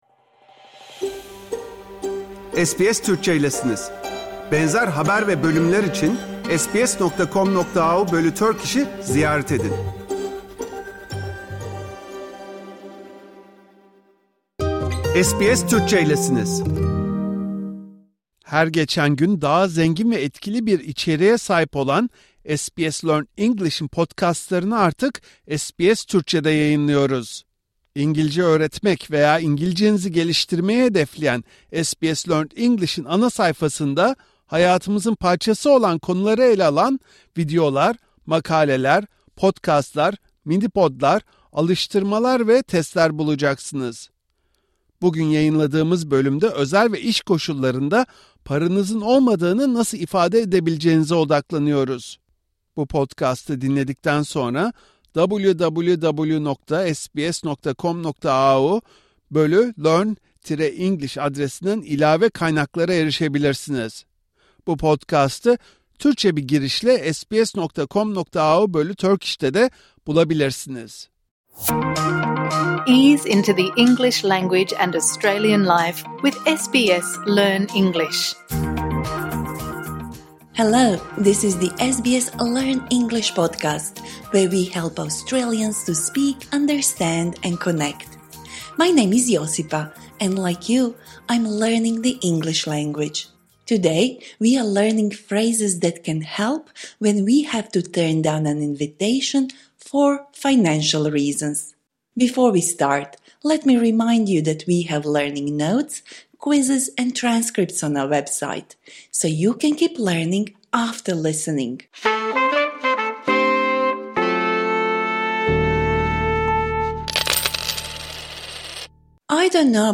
Bu İngilizce dersi orta üstten ileri seviyeye kadar olan kişiler için.